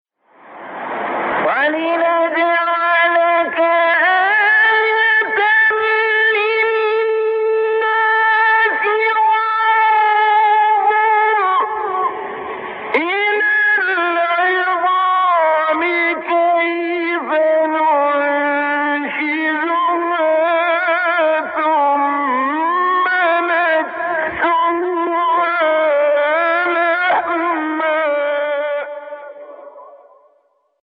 10 مقطع در مقام «صبا» با صوت قاریان مصری
گروه شبکه اجتماعی: فرازهایی از تلاوت قاریان برجسته مصری را که در مقام صبا اجرا شده‌ است، می‌شنوید.